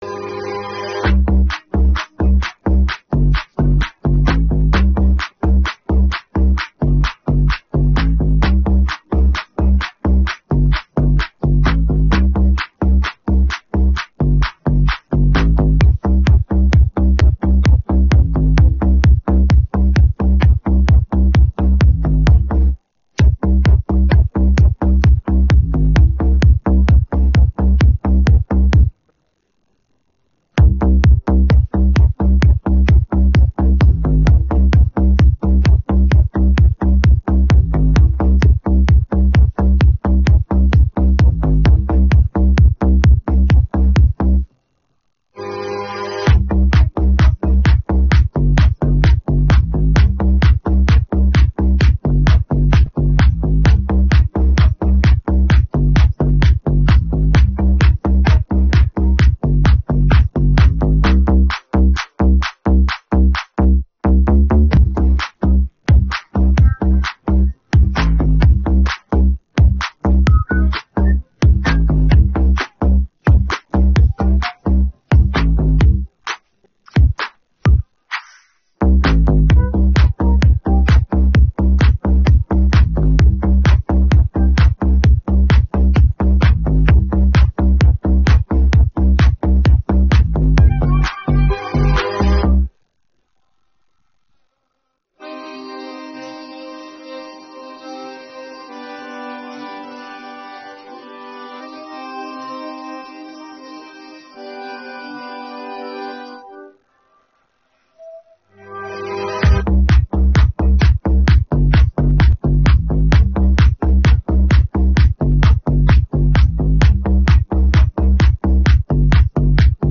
Українські хіти караоке Описание